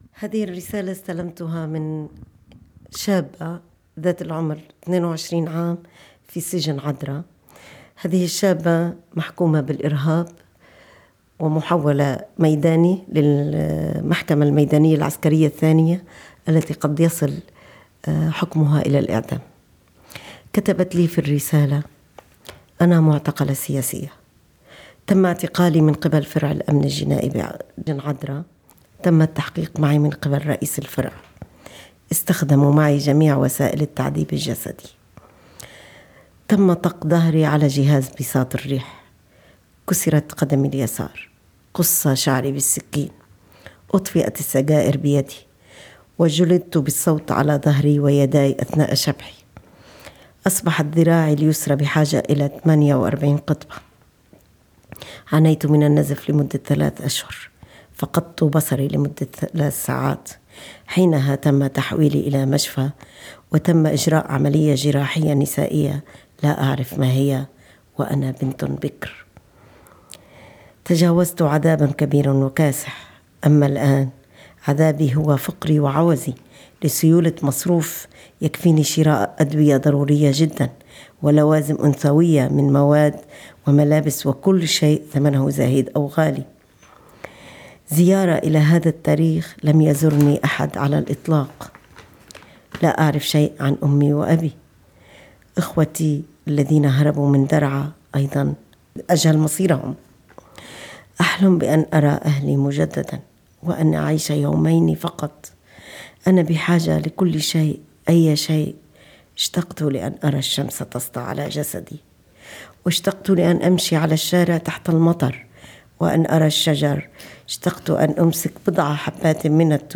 رسالة المعتقلة الشابة